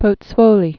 (pōt-swōlē)